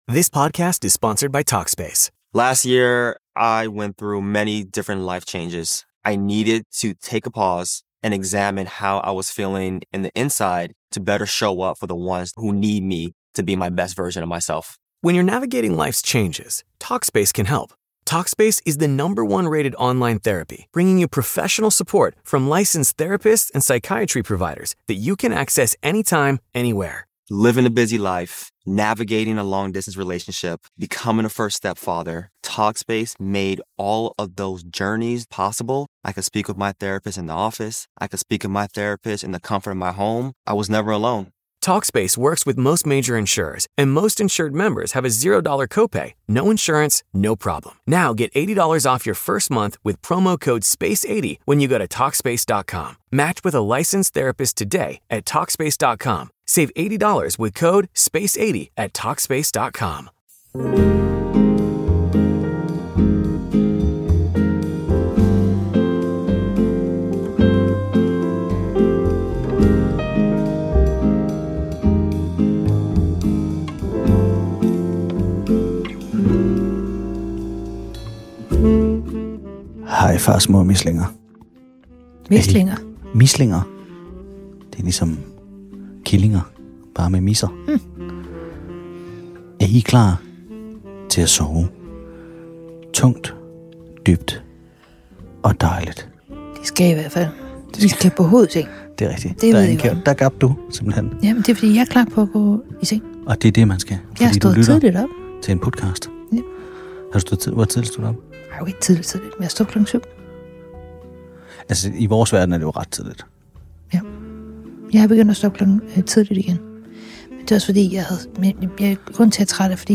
Nu skal du sove til en kropslammende kedelig samtale om kaffe.